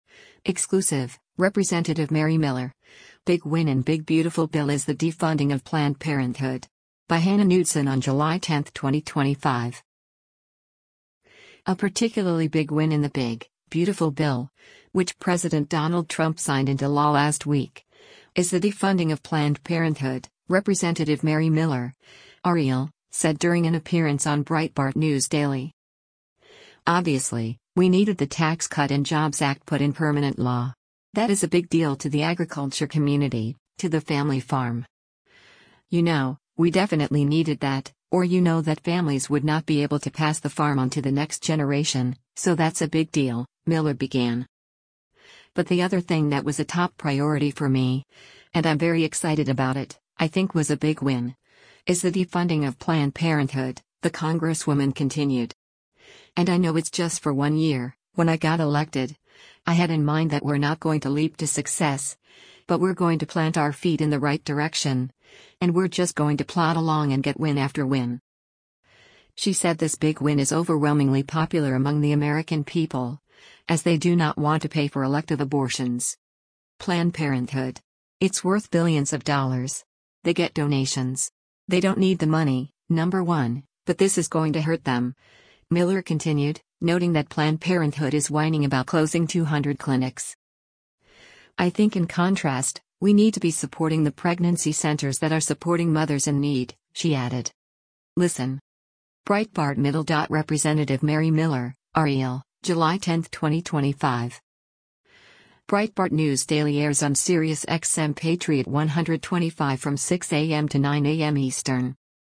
A particularly “big win” in the big, beautiful bill — which President Donald Trump signed into law last week — is the defunding of Planned Parenthood, Rep. Mary Miller (R-IL) said during an appearance on Breitbart News Daily.